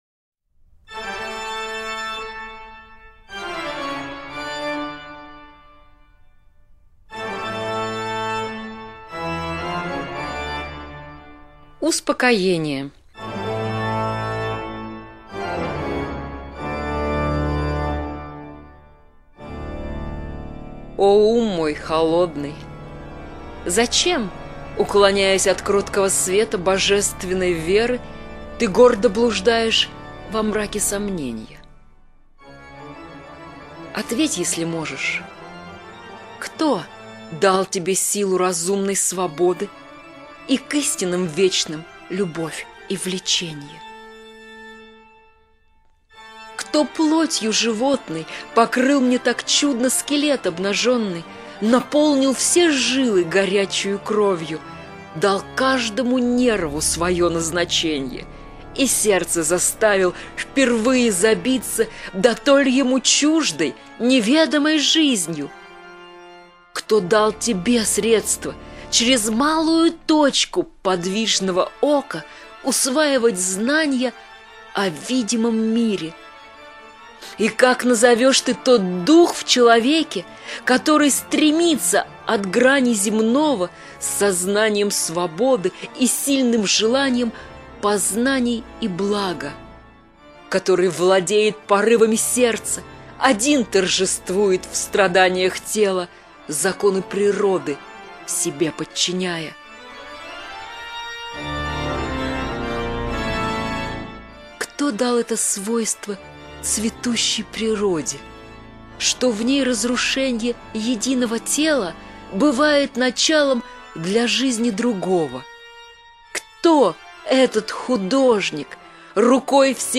1. «Радио Логос – Литературные чтения. Иван Саввич Никитин Успокоение» /